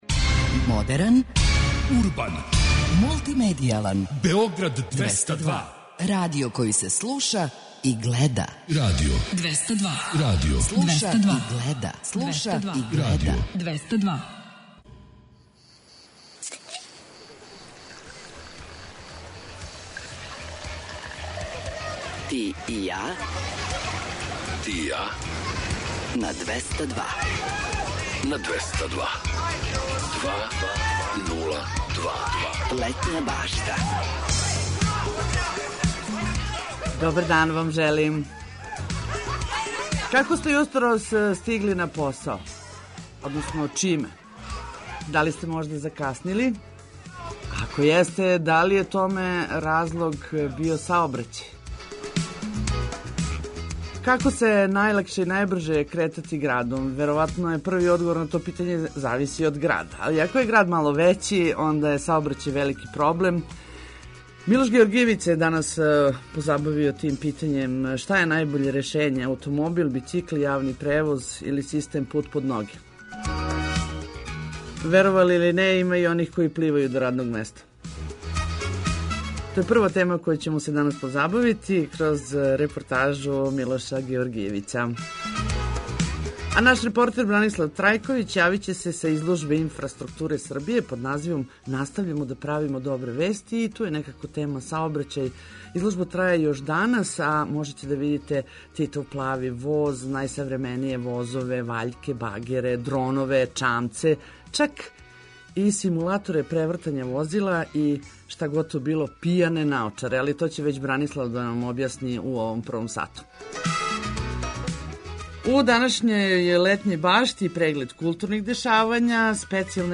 Лепе вести, сервисне информације и добра музика се подразумевају.